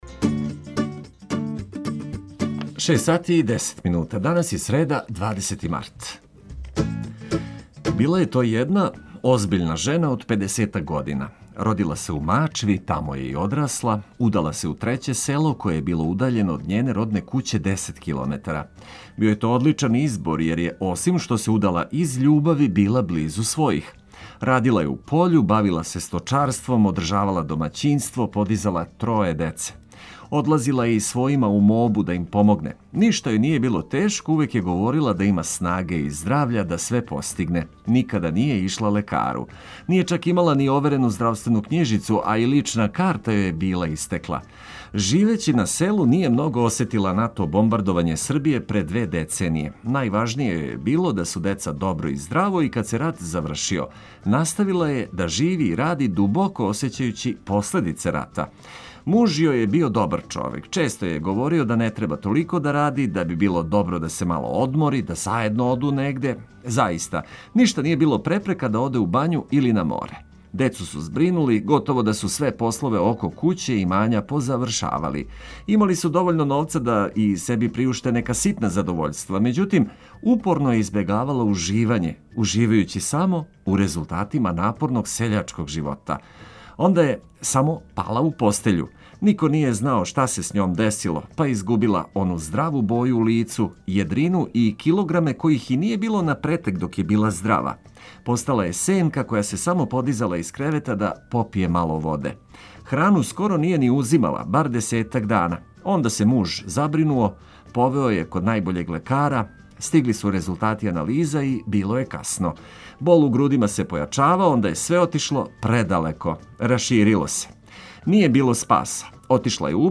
Обећавамо сјајно дружење уз блиставу музику и пролећне приче.